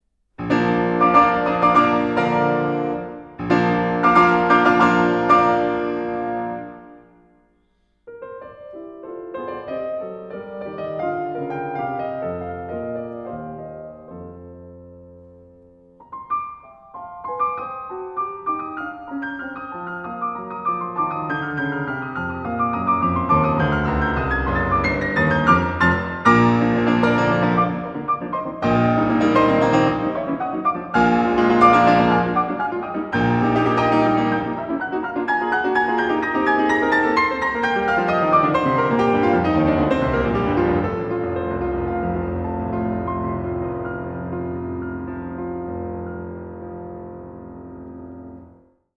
No. 29 - Beethoven 32 Piano Sonatas
It is determined by descending thirds and quite contrapuntal, which marks Beethoven’s late work.